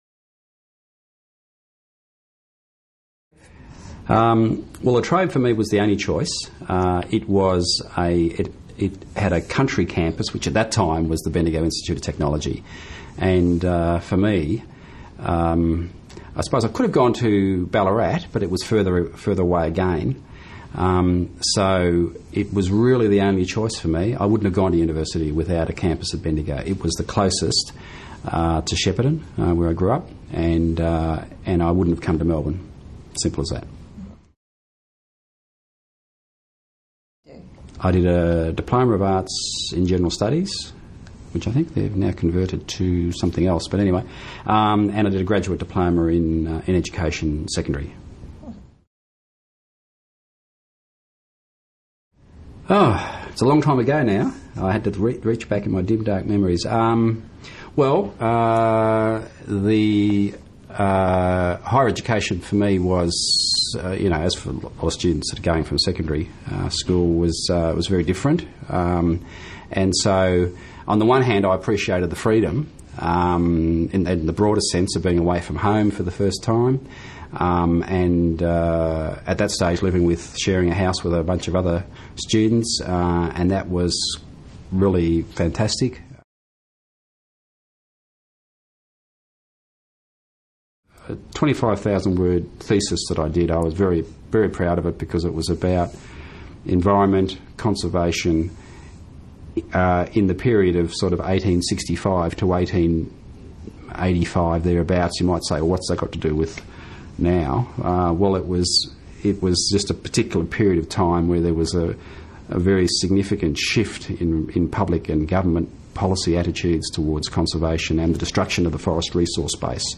We were fortunate to have the opportunity to interview some of the Distinguished Alumni Awards winners about their time at La Trobe University.